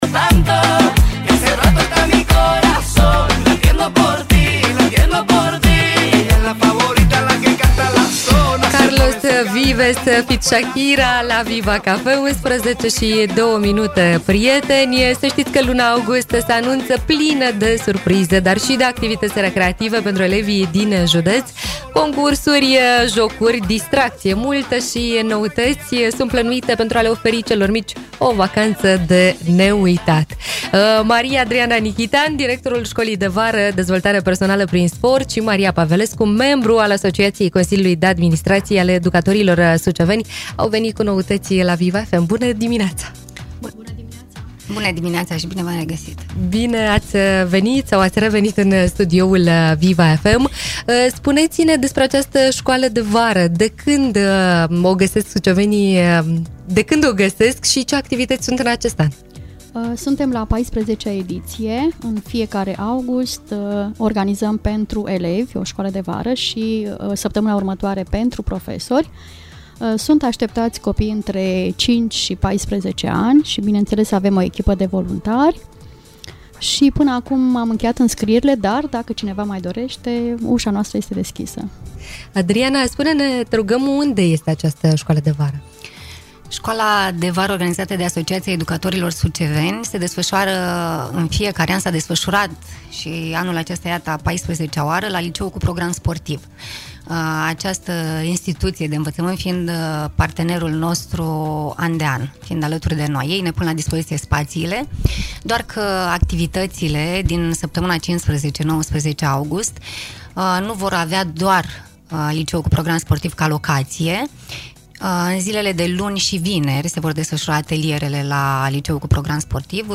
interviu-scoala-de-vara.mp3